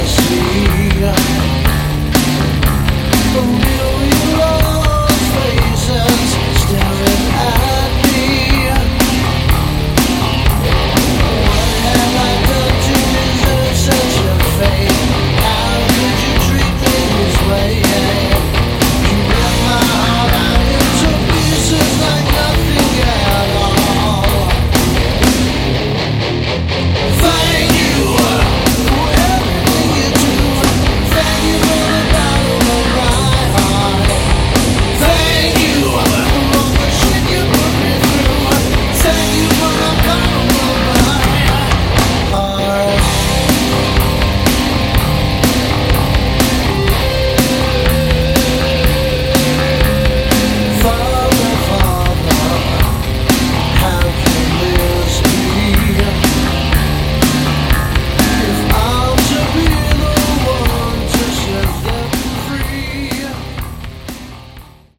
Category: Hard Rock
vocals, guitars
guitar
bass
drums